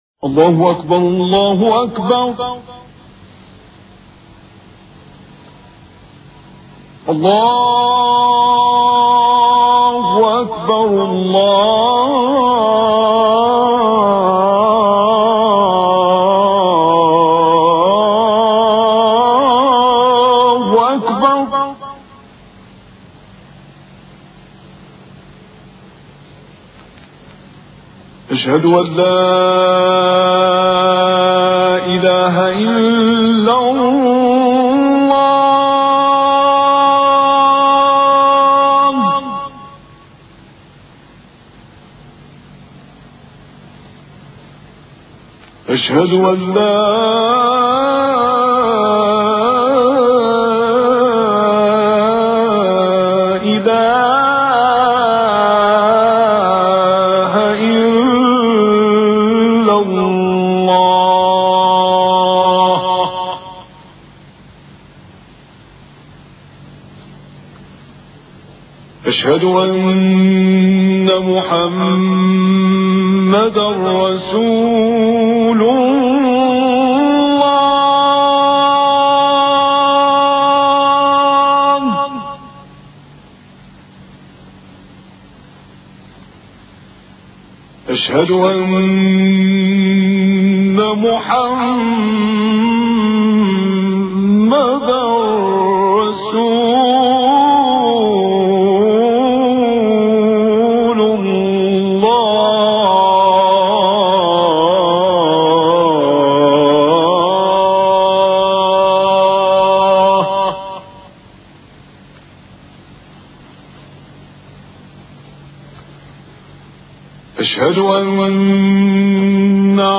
Athan.mp3